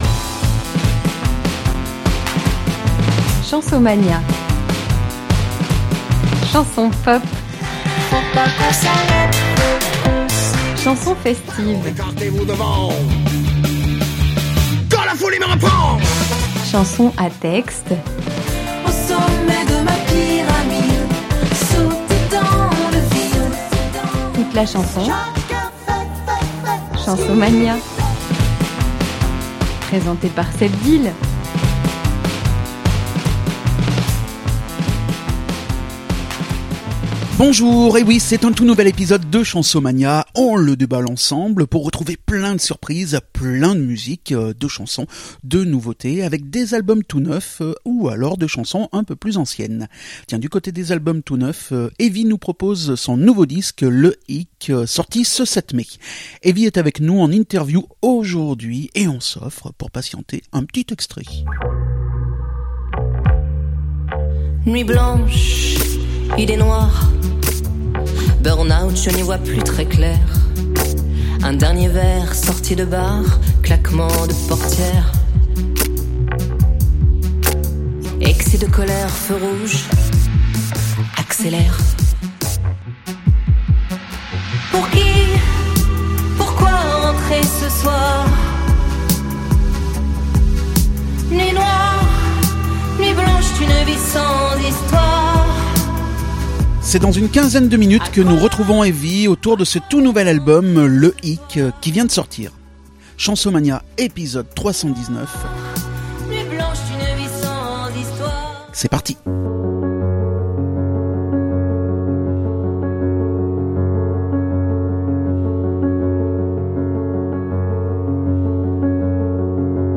Elle est avec nous en interview.